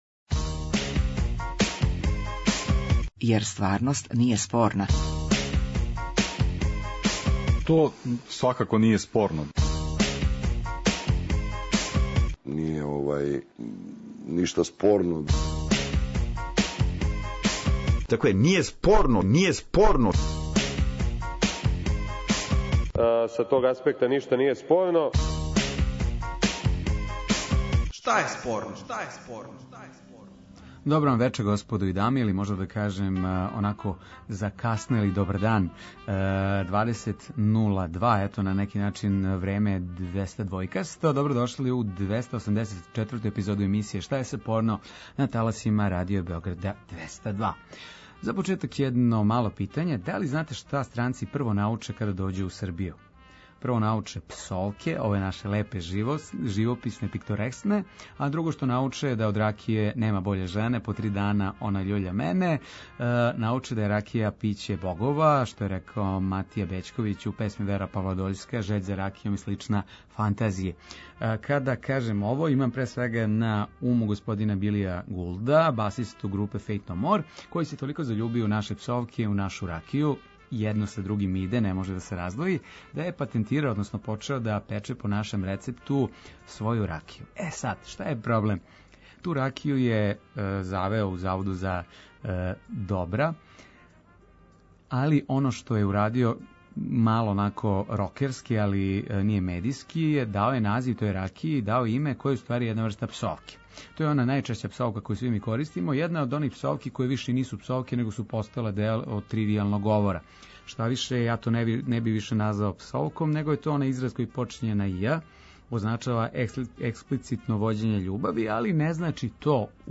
Радијски актуелно - забавни кабаре интерактивног карактера.